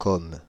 Commes (French pronunciation: [kɔm]
Fr-Commes.ogg.mp3